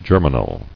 [ger·mi·nal]